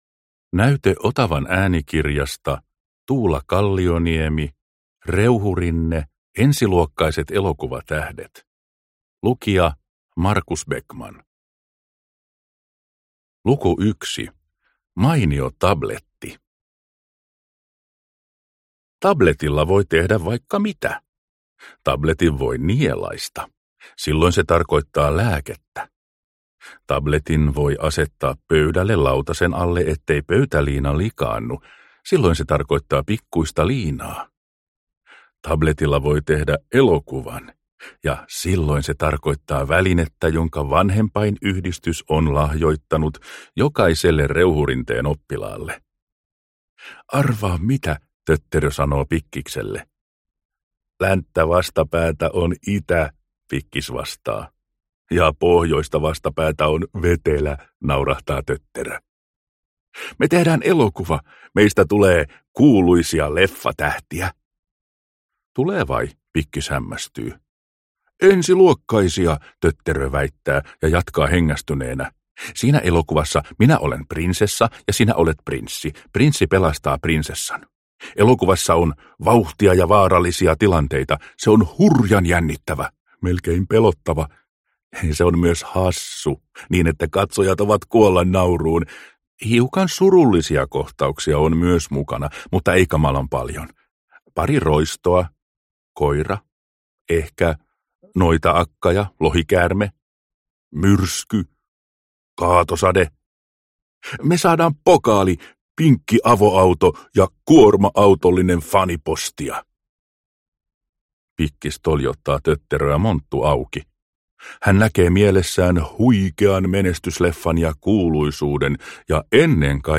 Hauskoja Reuhurinne-tarinoita äänikirjoina!